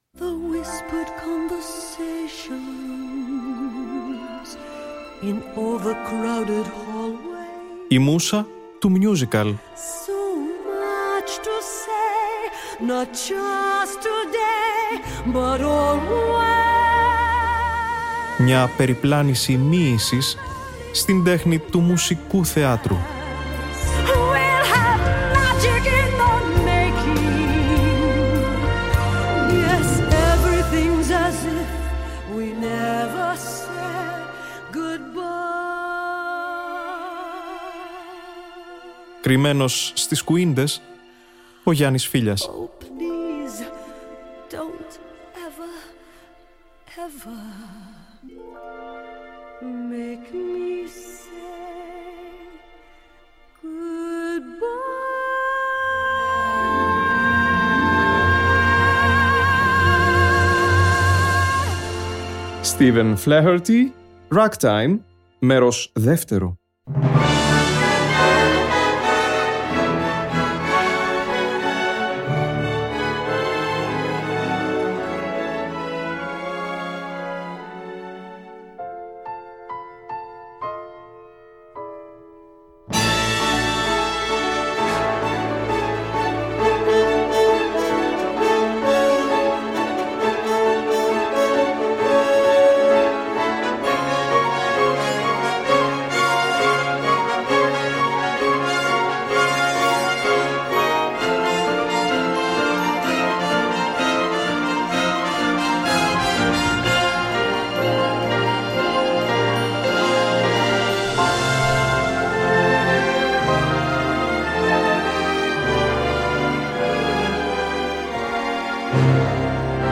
Broadway musical